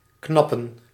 Ääntäminen
IPA: /ˈknɑ.pə(n)/